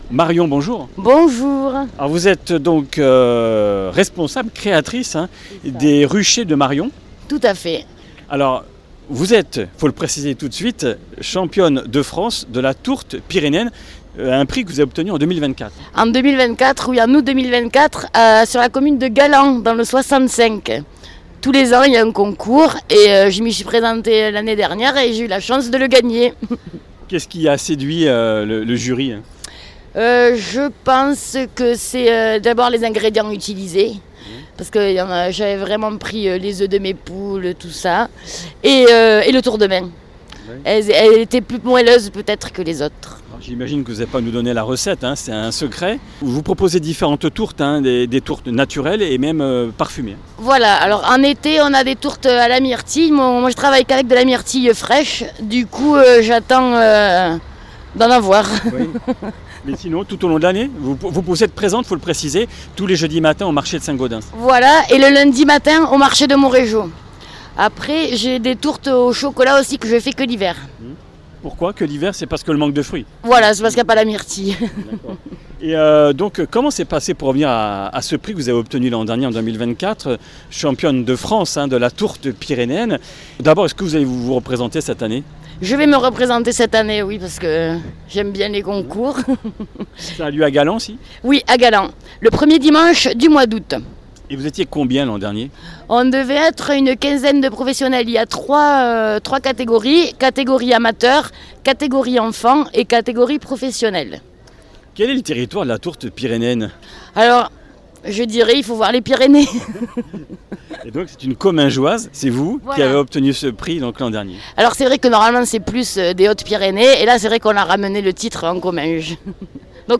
Comminges Interviews du 16 juin